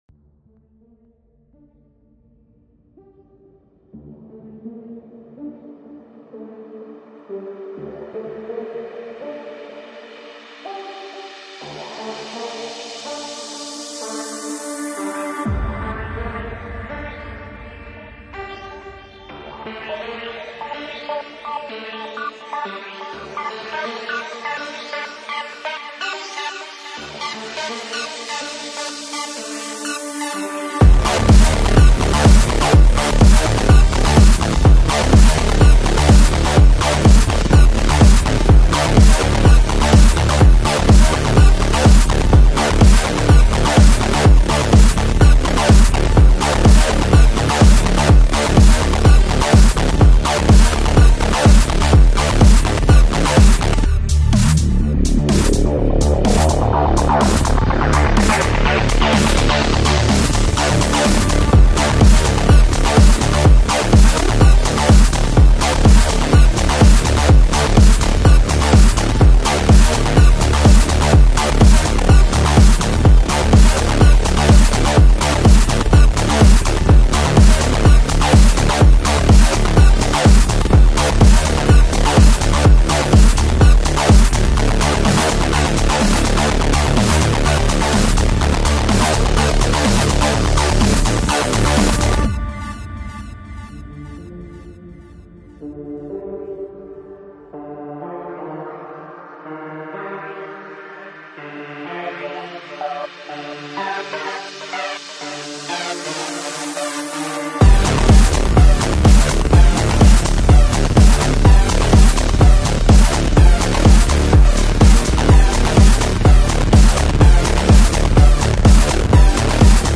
Industrial Music "Absorb" Mp3 Format.